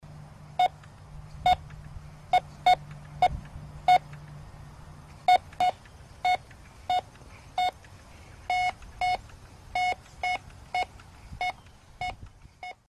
Звуки металлоискателя
На этой странице собраны звуки металлоискателей разных типов: от монотонных сигналов старых моделей до современных многозональных тонов.